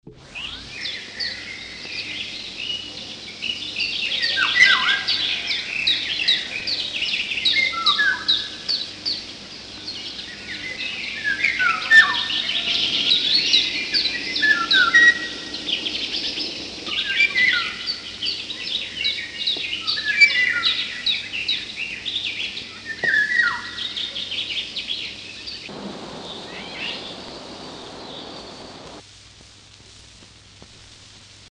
1935 erschienen im Verlag von Hugo Bermühler in Berlin-Licherfelde insgesamt drei Schallplatten mit dem Titel „Gefiederte Meistersänger“, die die Singstimmen von Vögeln dokumentieren.
Der Pirol
2401-2-A-Pirol.mp3